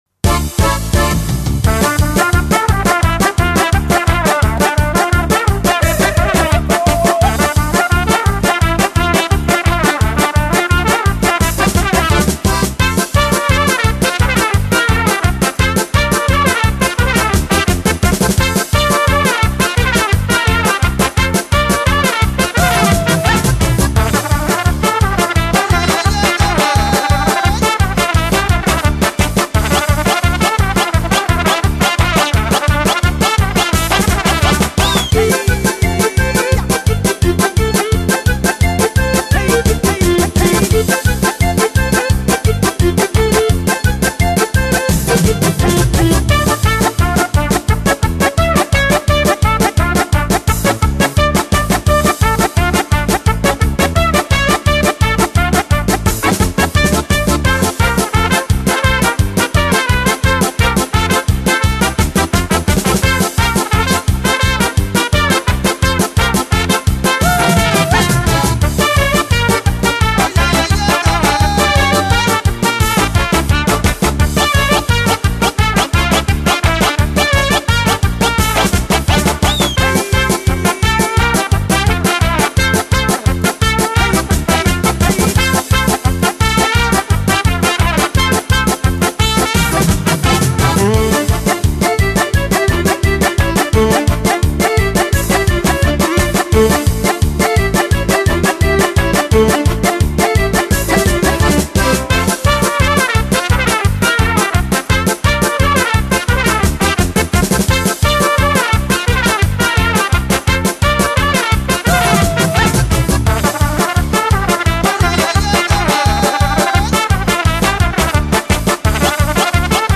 POLKA___UKRAINSKAYA_get_tune_.mp3